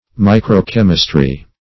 Search Result for " micro-chemistry" : The Collaborative International Dictionary of English v.0.48: microchemistry \mi`cro*chem"is*try\, micro-chemistry \mi`cro-chem"is*try\, n. [Micro- + chemistry.]